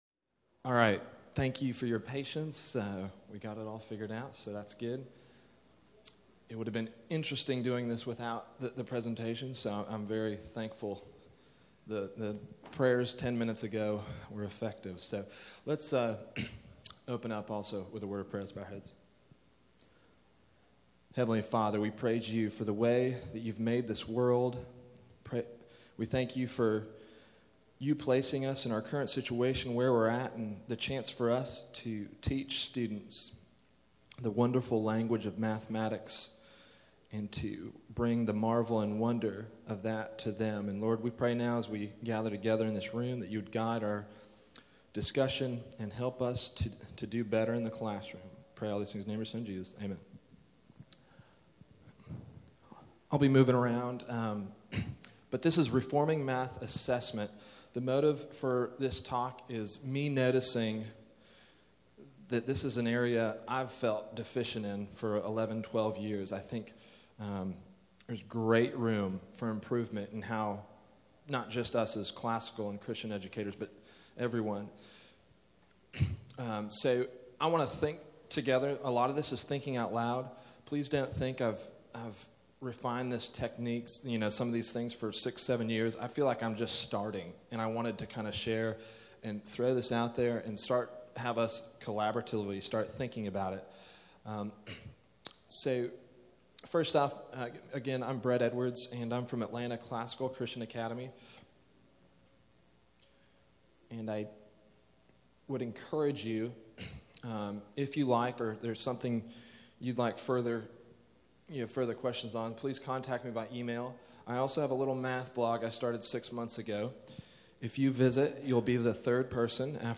2012 Workshop Talk | 0:55:27 | All Grade Levels, Math